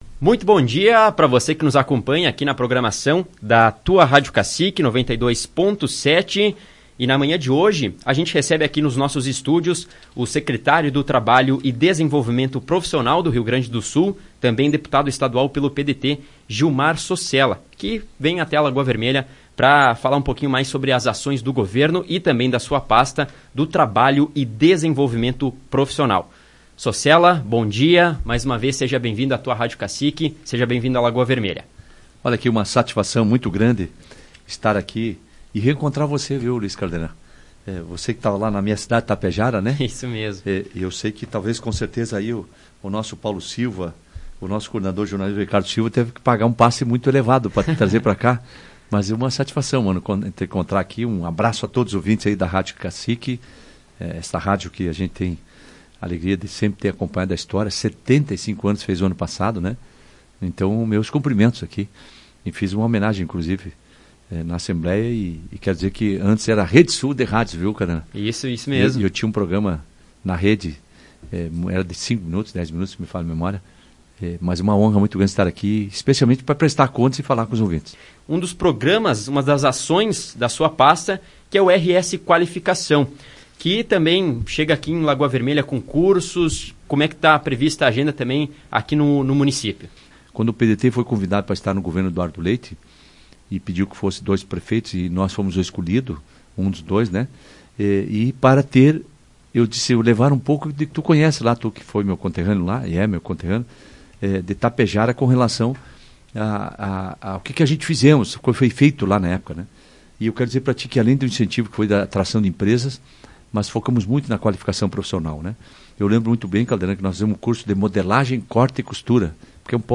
Em entrevista a Tua Rádio Cacique, Sossella enfatizou a necessidade da colaboração entre Estado e municípios gaúchos para implementar políticas públicas eficazes.